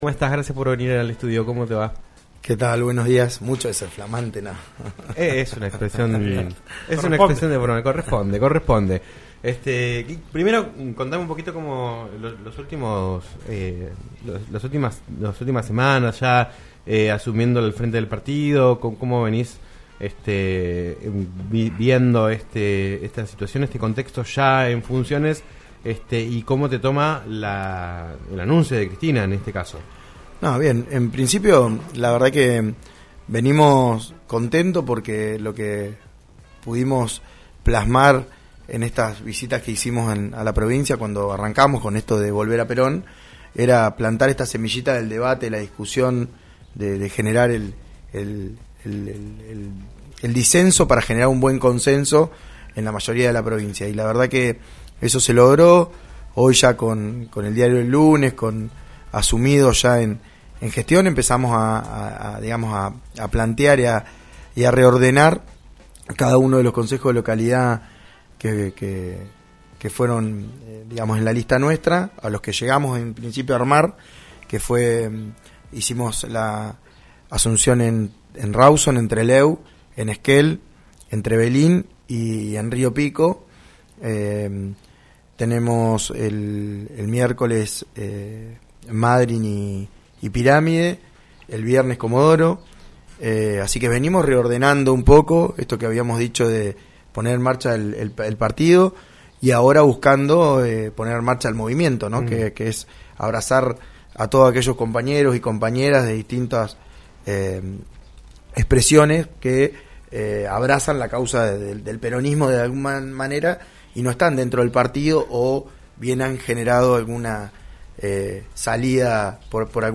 Gustavo Fita, diputado provincial por Arriba Chubut y flamante presidente del Partido Justicialista Chubut, visitó los estudios de LaCienPuntoUno para hablar en "Un Millón de Guanacos" sobre la realidad del partido, tanto a nivel local como nacional con la figura de Cristina Kirchner como la posible conductora.